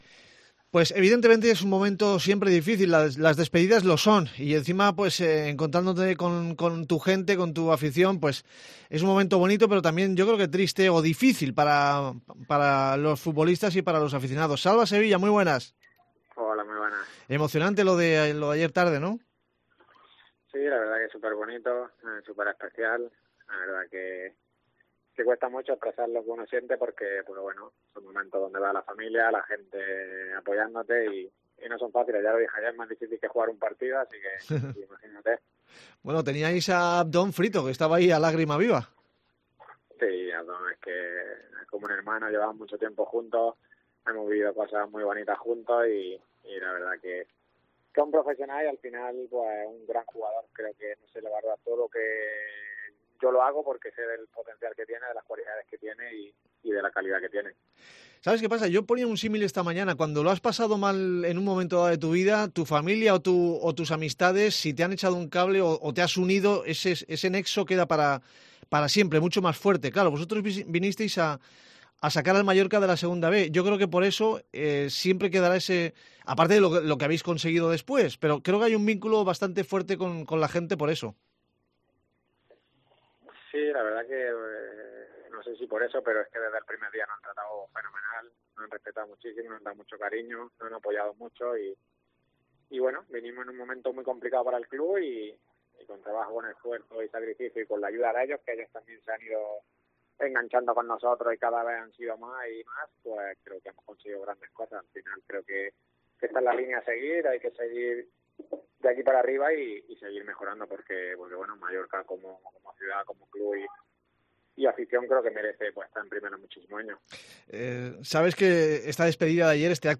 Habla muy claro Salva Sevilla en esta entrevista para despedirse de la afición del RCD Mallorca.